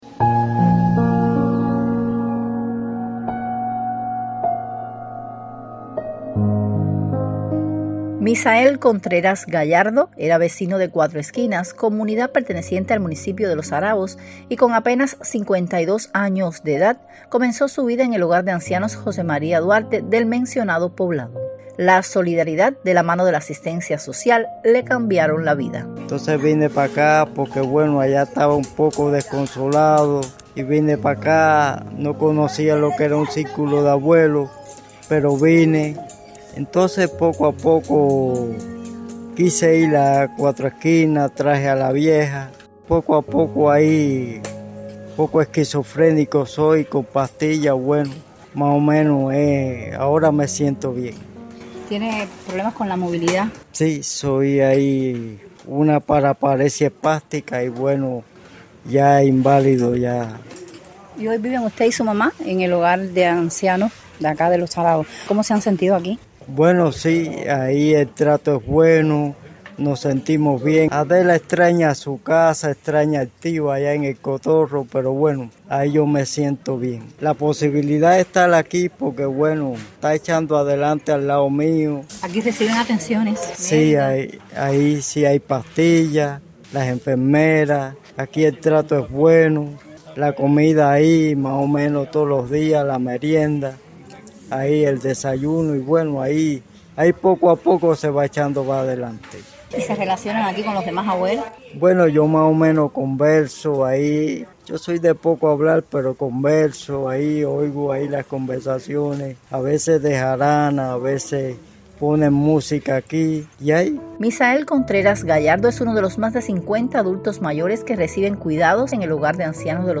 La solidaridad de la mano de la asistencia social le cambiaron la vida, así lo cuenta el propio protagonista.